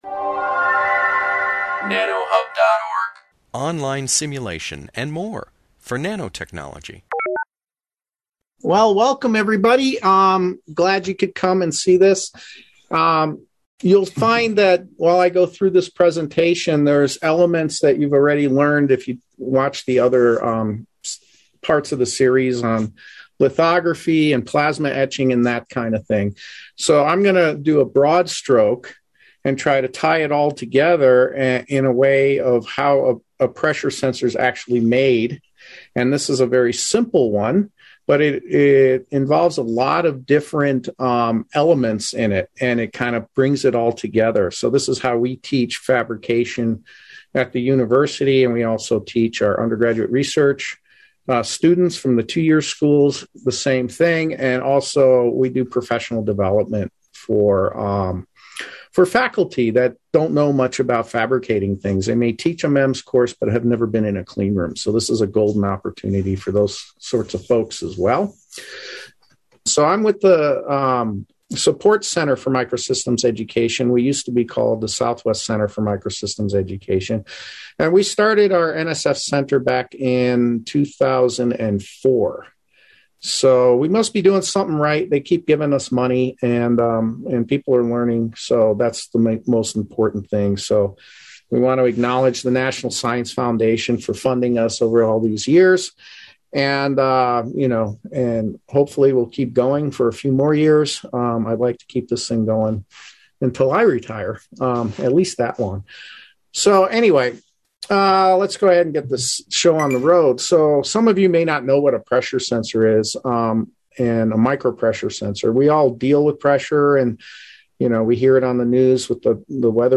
This webinar, published by the Nanotechnology Applications and Career Knowledge Support (NACK) Center at Pennsylvania State University, focuses on the construction of a simple microelectromechanical systems (MEMS) pressure sensor.